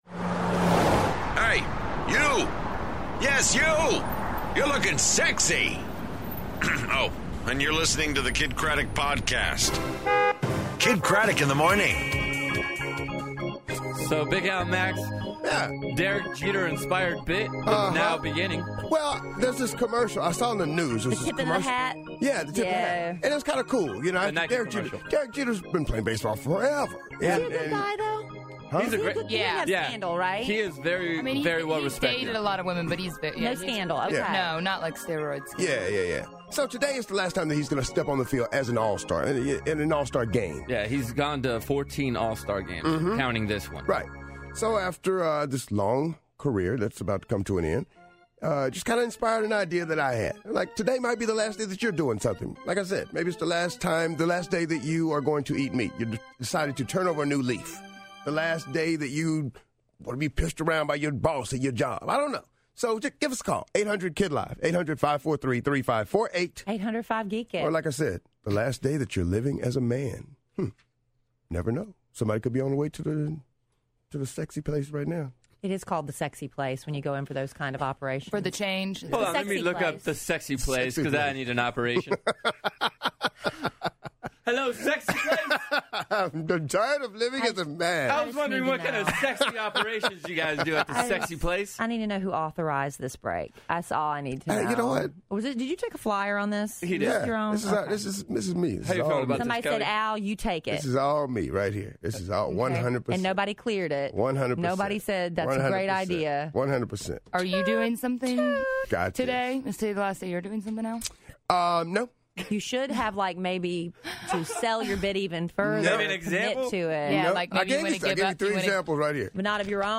Mike The Situation (From Jersey Shore) Is On The Phone, And What's In Your Purse?